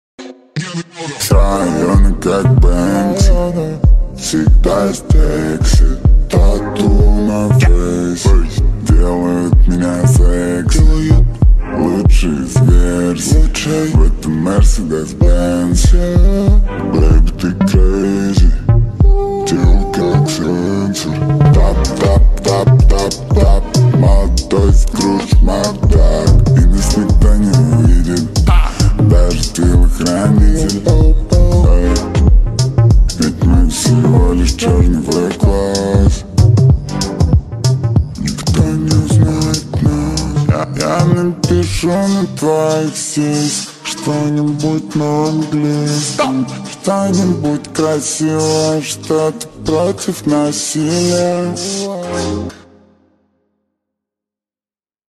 • Качество: 192 kbps, Stereo